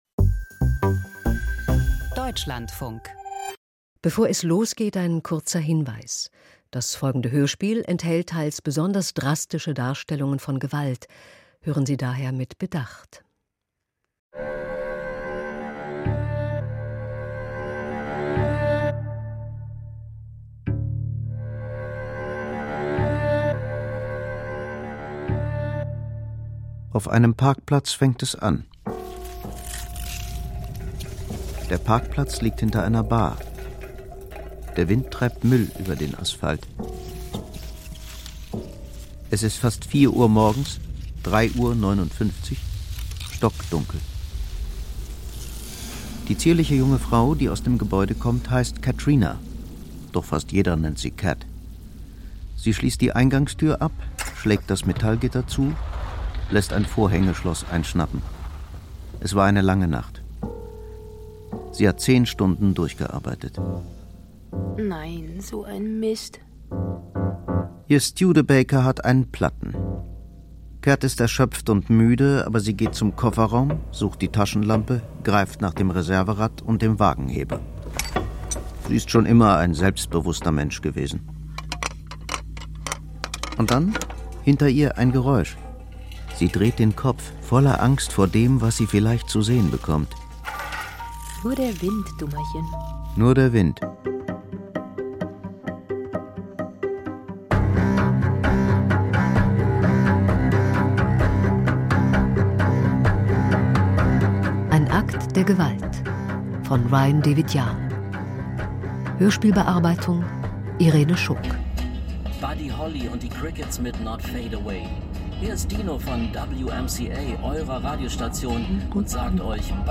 Krimi-Hörspiel: Über den Bystander-Effekt - Ein Akt der Gewalt